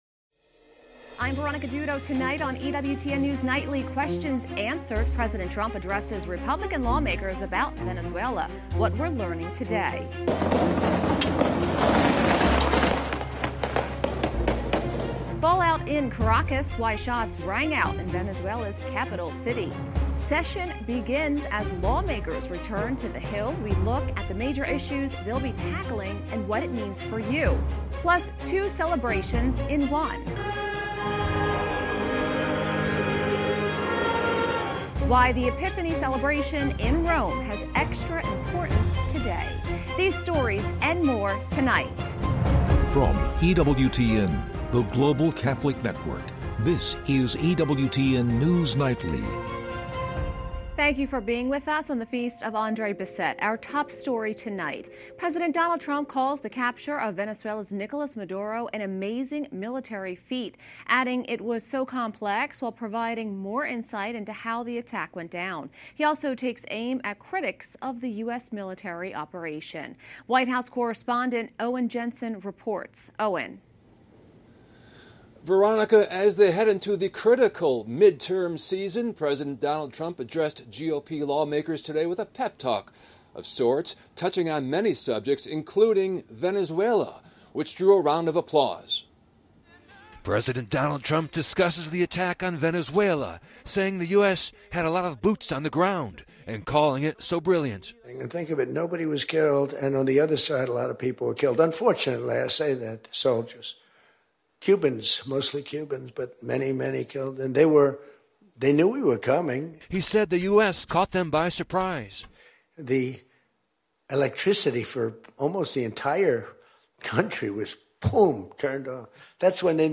EWTN News Nightly is our daily news and analysis program presenting breaking Catholic News worldwide, top stories, and daily reports from the White House, Capitol Hill, and Rome.